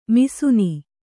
♪ misuni